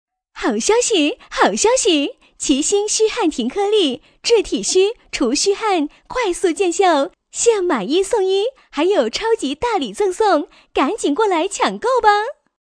【女90号促销】国语促销
【女90号促销】国语促销.mp3